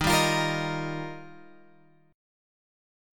D#add9 chord {11 10 x 10 11 11} chord